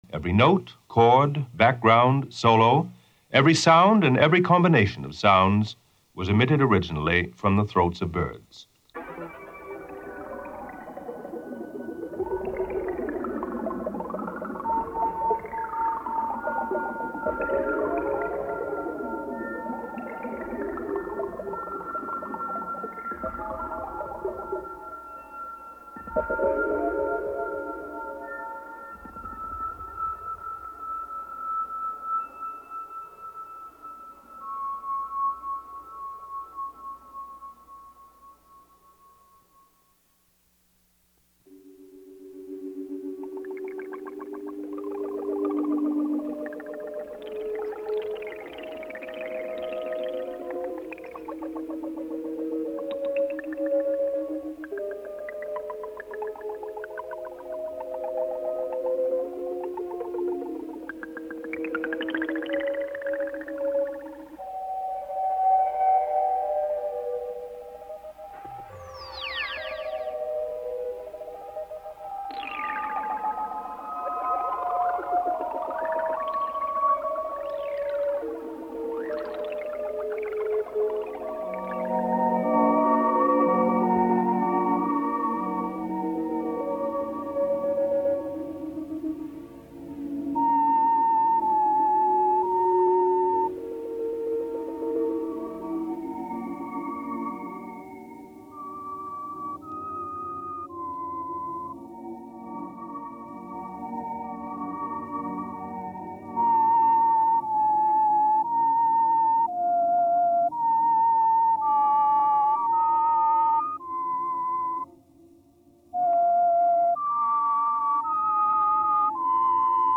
dj set